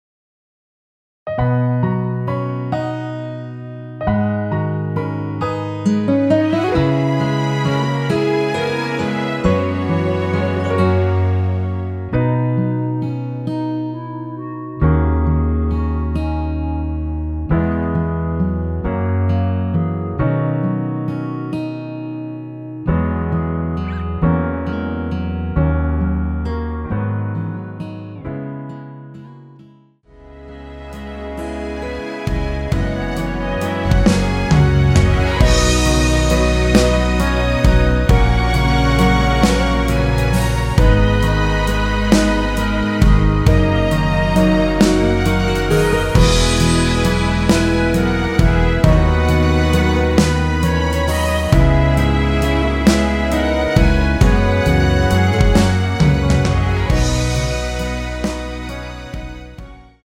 원키에서(-1)내린 MR입니다.
◈ 곡명 옆 (-1)은 반음 내림, (+1)은 반음 올림 입니다.
앞부분30초, 뒷부분30초씩 편집해서 올려 드리고 있습니다.
중간에 음이 끈어지고 다시 나오는 이유는